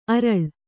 ♪ araḷ